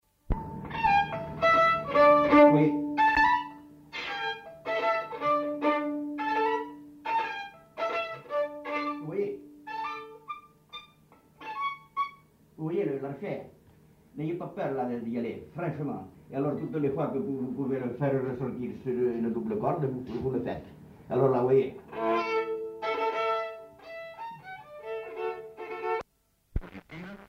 Aire culturelle : Astarac
Lieu : Orbessan
Genre : morceau instrumental
Instrument de musique : violon
Danse : polka des bébés